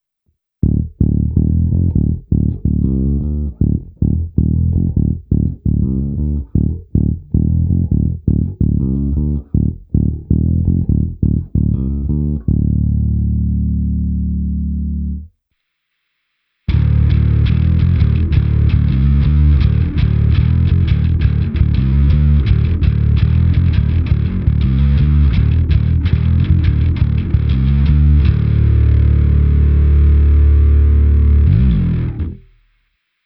Další zvuk, který jsem objevil, je parádně naprděný. Je to jen na kobylkový snímač, přičemž jsem stáhnul skoro úplně tónovou clonu, tedy cca o 90%.
Ukázka do zvukovky – B100 BT-90